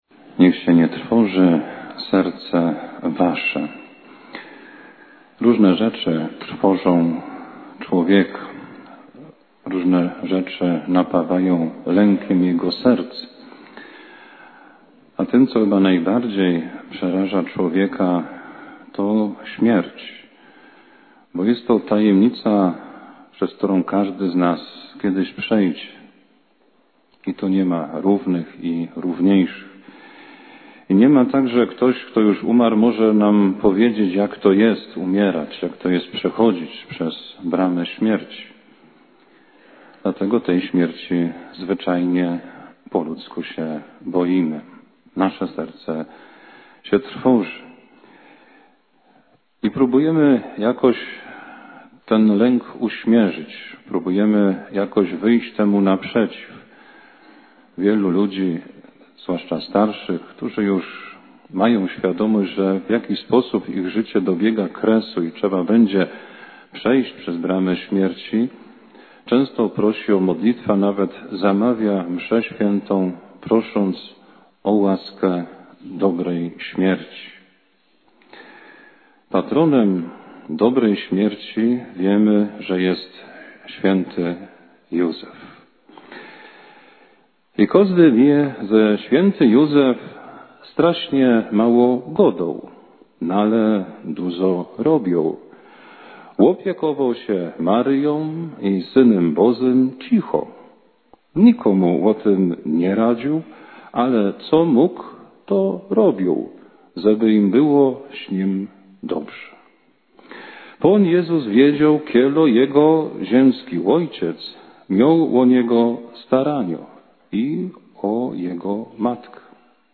Kazanie ze Wspomnienia Wszystkich Wiernych Zmarłych – 2.11.2025